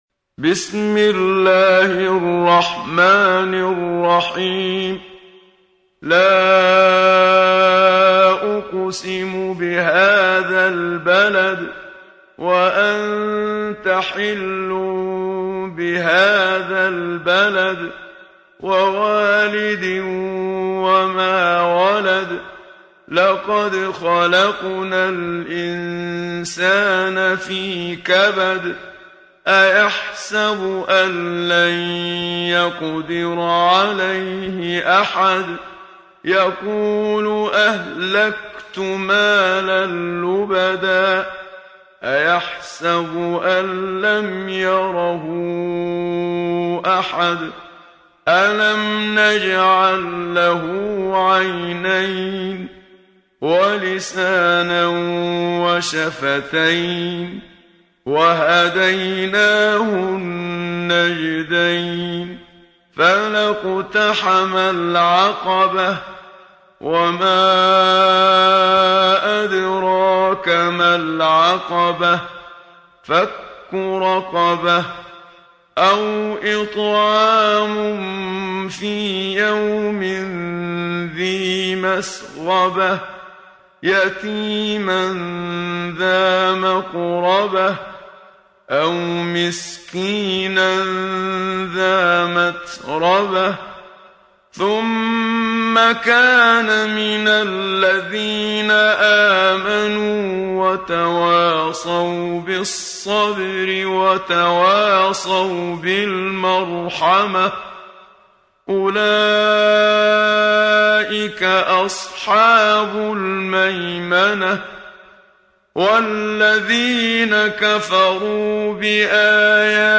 سورة البلد | القارئ محمد صديق المنشاوي